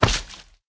sounds / mob / magmacube